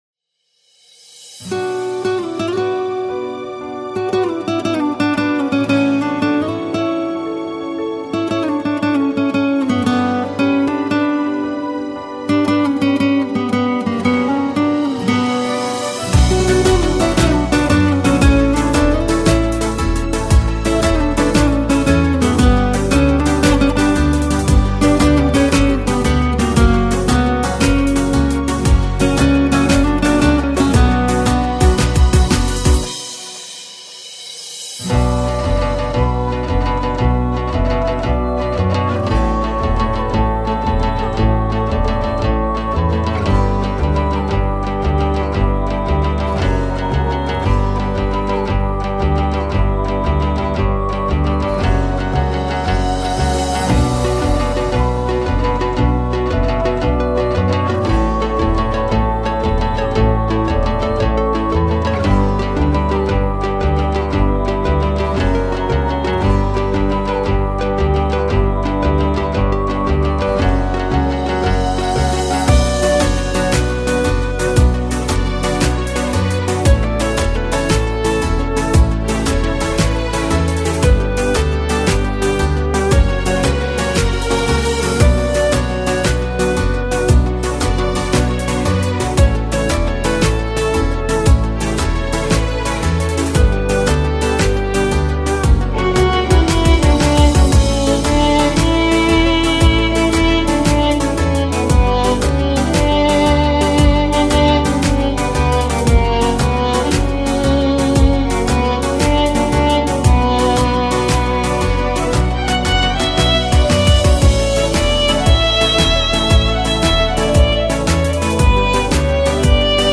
بی کلام
سرود حماسی
پرشور و ملی‌ـ‌مذهبی